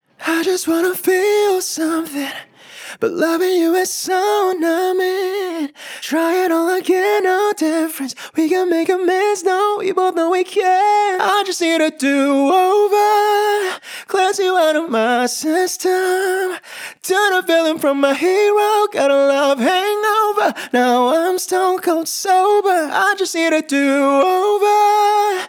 Zedd-HQ-POP-TUNED-Vocal.wav